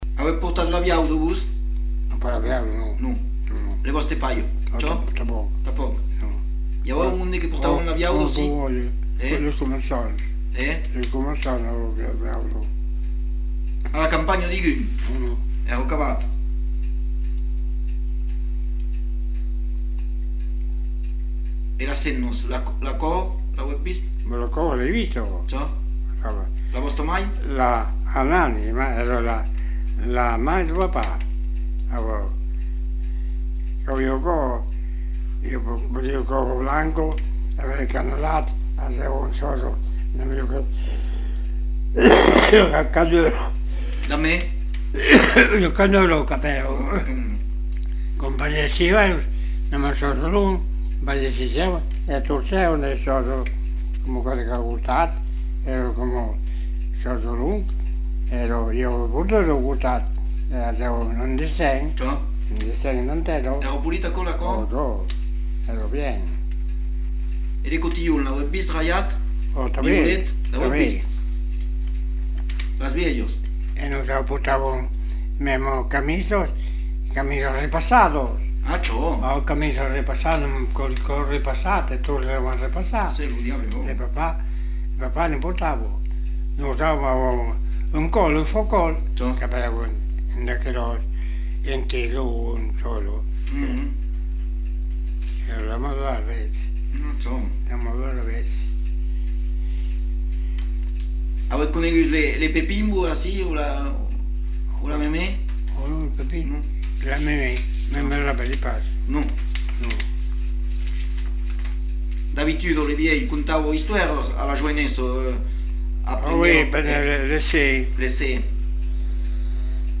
Lieu : Espaon
Genre : témoignage thématique